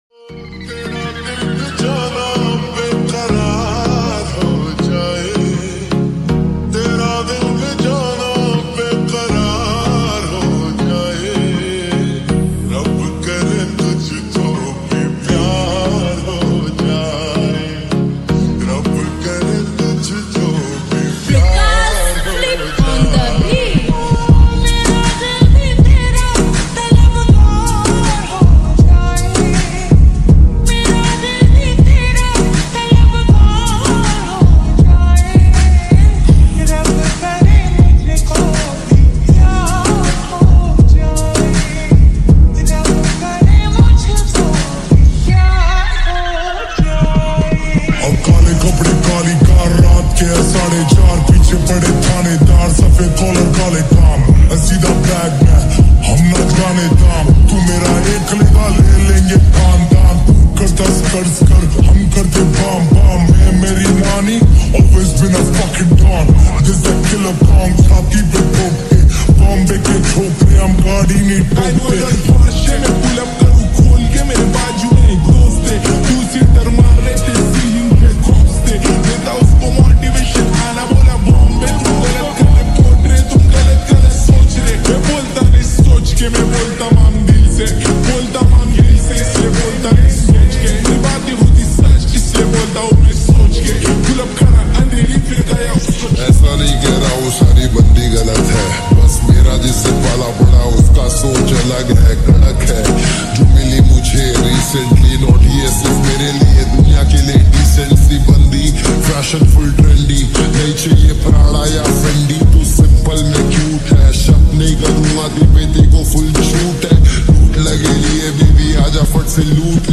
slowed + reverb